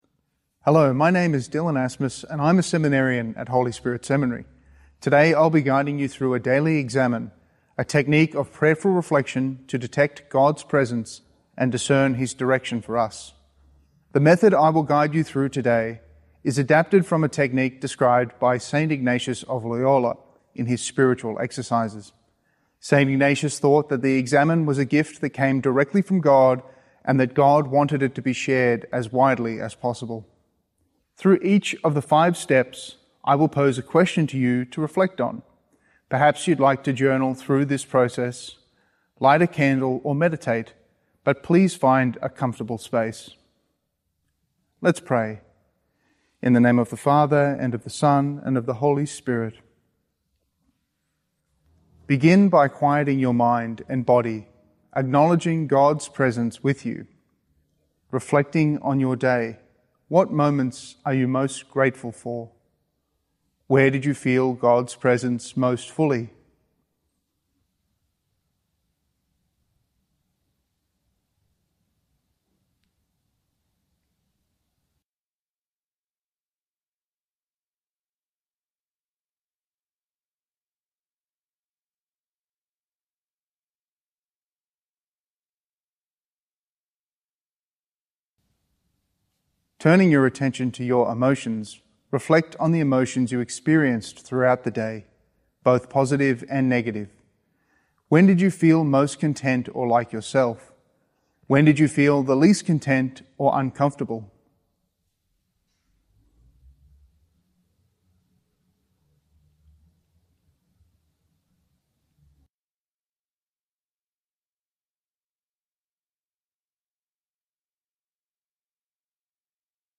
Archdiocese of Brisbane A guided Daily Examen prayer